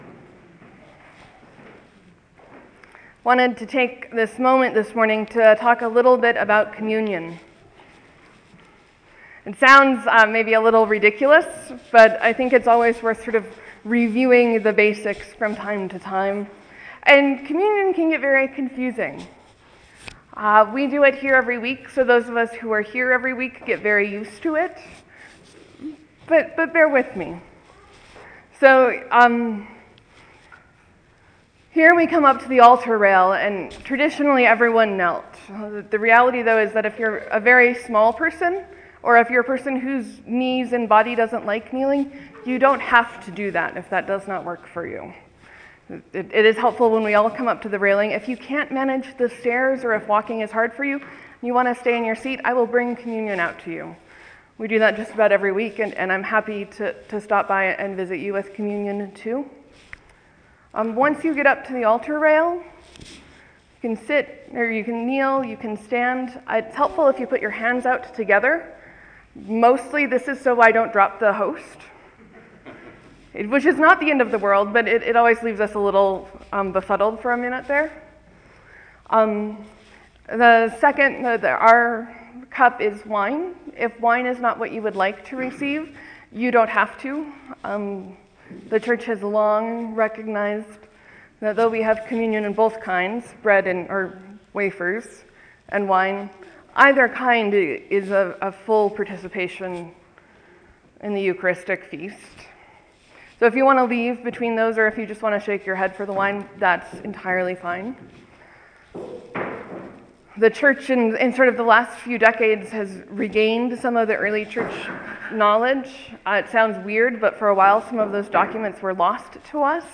Sermon: I preached about a Christian response to the deaths in Paris, Beirut, and Baghdad. How do we respond to violence, even when it’s done in the name of a peaceful religion?